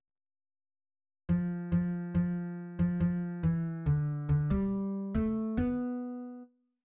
<< %{ \new Staff \with {instrumentName = "S A" shortInstrumentName = "S A"} << \set Staff.midiMinimumVolume = #0.3 \set Staff.midiMaximumVolume = #0.7 \set Score.currentBarNumber = #1 \bar "" \tempo 4=70 \time 4/4 \key bes \major \new Voice = "s1" \relative c '' { \voiceOne bes2 a bes a bes4 a c a bes2 a } \new Voice = "s2" \relative c '{ \voiceTwo ees2 d ees d ees4 d f d ees2 d } >> \new Lyrics \lyricsto "s1" {\set fontSize = #-2 s' brent s' brent und- zer shte- tl bre -nt de } %} %{ \new Staff \with {instrumentName = "A" shortInstrumentName = "A"} << \set Staff.midiMinimumVolume = #0.3 \set Staff.midiMaximumVolume = #0.5 \set Score.currentBarNumber = #1 \bar "" \tempo 4=70 \time 4/4 \key bes \major \new Voice = "a1" \fixed c ' { \voiceOne g1 g g g } \new Voice = "a2" \fixed c ' { \voiceTwo d1 d d d } >> \new Lyrics \lyricsto "a1" {\set fontSize = #-2 } \repeat volta 2 %} %{ \new Staff \with {instrumentName = "T" shortInstrumentName = "T"} << \set Staff.midiMinimumVolume = #0.3 \set Staff.midiMaximumVolume = #0.5 \set Score.currentBarNumber = #1 \bar "" \tempo 4=70 \time 4/4 \key bes \major \new Voice = "t1" \fixed c ' { \voiceOne g1 g g g } \new Voice = "t2" \fixed c ' { \voiceTwo d1 d d d } >> \new Lyrics \lyricsto "t1" {\set fontSize = #-2 } \repeat volta 2 %} \new Staff \with {midiInstrument = #"acoustic bass" instrumentName = "B" shortInstrumentName = "B"} << \set Staff.midiMinimumVolume = #14.7 \set Staff.midiMaximumVolume = #15.9 \set Score.currentBarNumber = #1 \bar "" \tempo 4=70 \time 6/8 \key c \major \clef bass \new Voice = "b1" \relative c { \voiceOne r4 r8 f8 f8 f8. f16 f8 e d d16 g8. a8 b4 r8 } { \new Voice = "b2" \relative c { \voiceOne \stemDown } } >> \new Lyrics \lyricsto "b1" {\set fontSize = #-2 Biz s’gist zikh in ey- bi- kn Ni- gn a- rayn } \new Lyrics \lyricsto "b1" {\set fontSize = #-2 } >> \midi{}